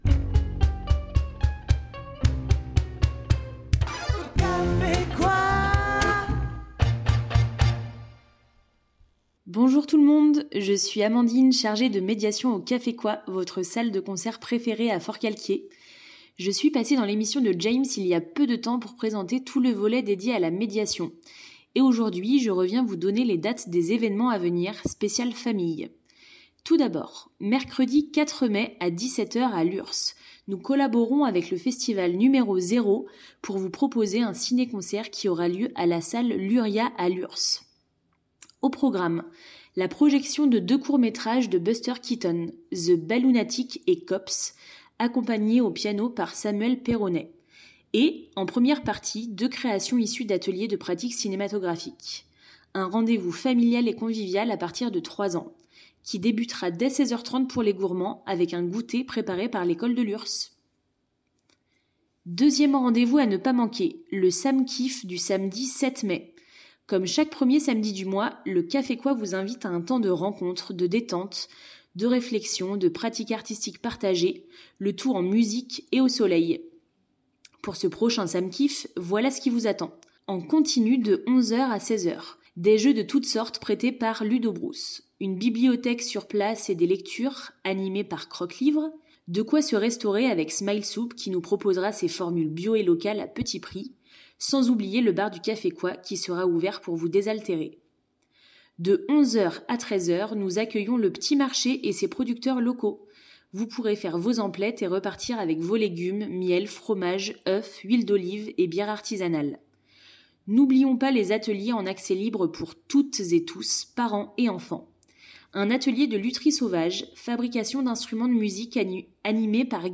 nous annonce le programme avec des extraits musicaux.